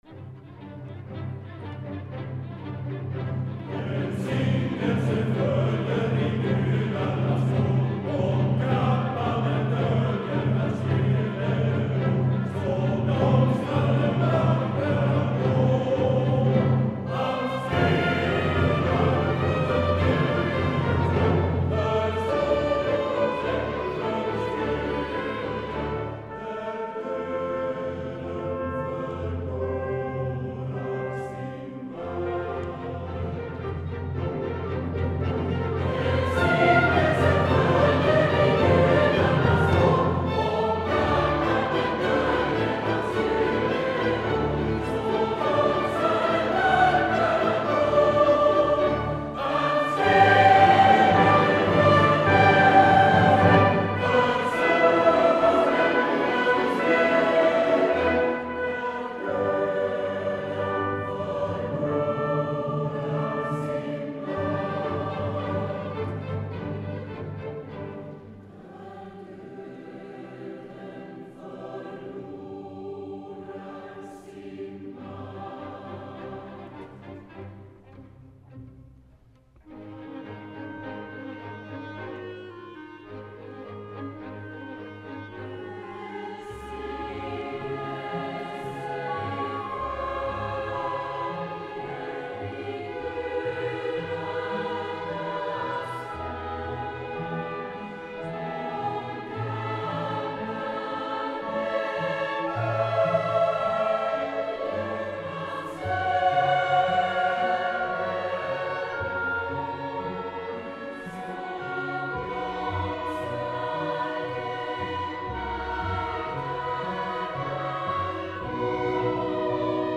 2007-09-29 Konsert Nicolai kyrka i Örebro, Örebro Orkesterförening
Lars-Erik Larsson: Förklädd Gud, Alla marcia